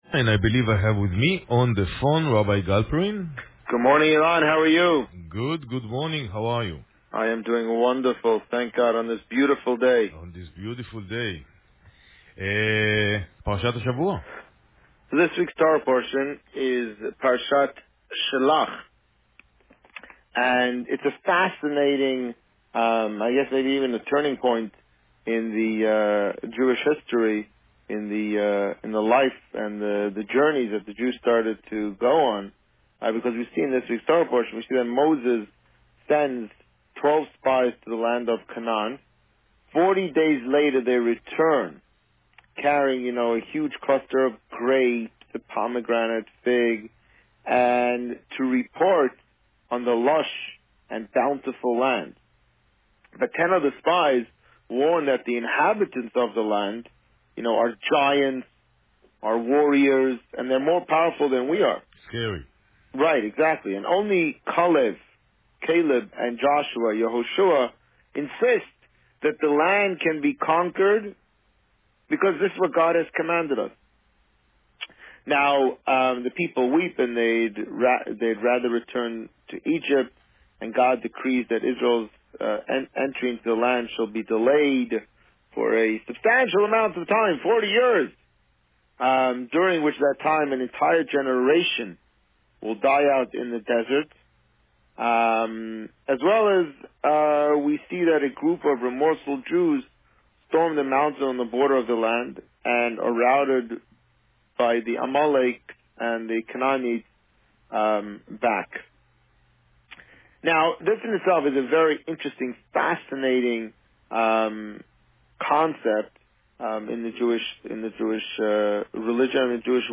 Today, the Rabbi spoke about Parsha Shlach. Listen to the interview here.